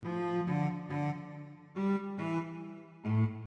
标签： 140 bpm House Loops Brass Loops 590.80 KB wav Key : Unknown
声道立体声